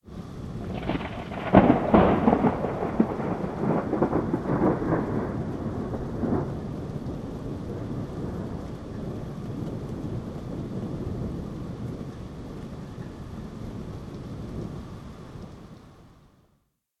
thunder_near.ogg